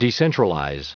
Prononciation du mot decentralize en anglais (fichier audio)
Prononciation du mot : decentralize